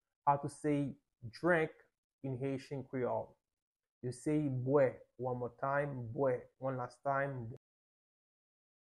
Listen to and watch “bwè” audio pronunciation in Haitian Creole by a native Haitian  in the video below:
5.How-to-say-Drink-in-Haitian-Creole-–-bwe-with-pronunciation.mp3